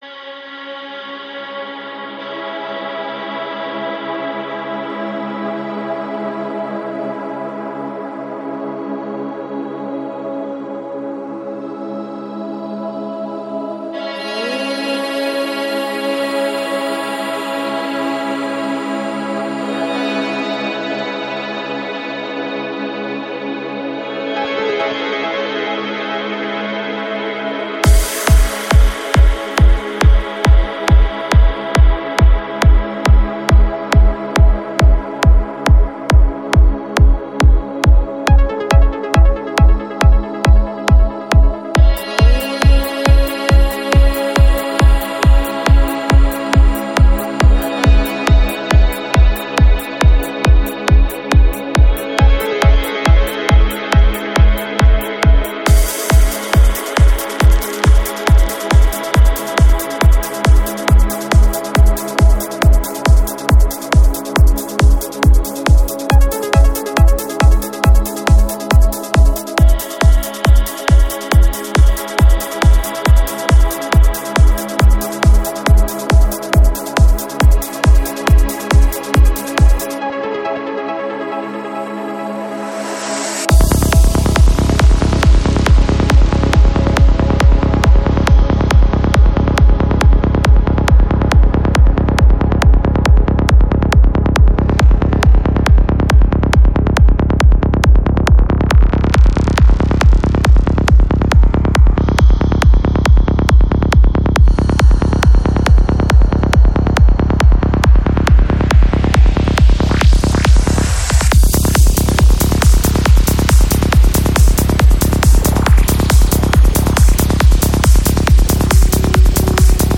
Жанр: Trance
Альбом: Psy-Trance